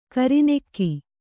♪ kari nekki